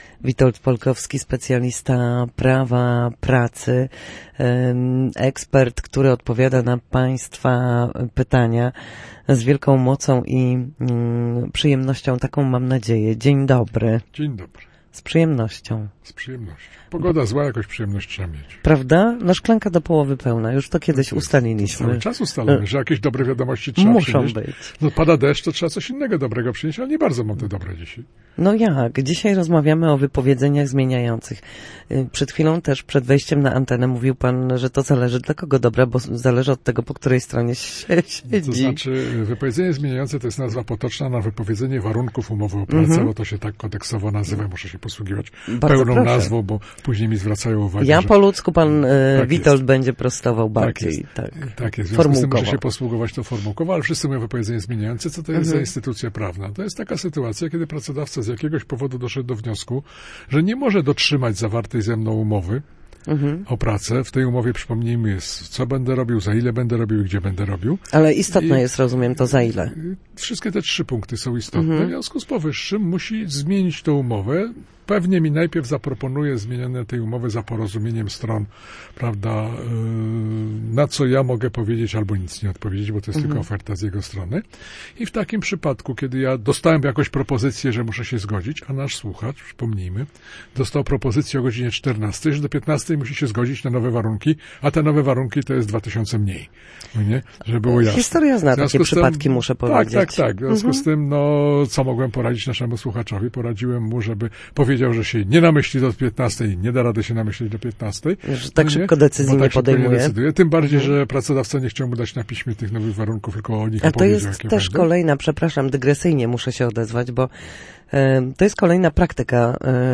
W każdy wtorek po godzinie 13:00 na antenie Studia Słupsk przybliżamy Państwu zagadnienia dotyczące prawa pracy.
Tym razem rozmowa dotyczyła umów zmieniających.